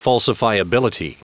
Prononciation du mot falsifiability en anglais (fichier audio)
Prononciation du mot : falsifiability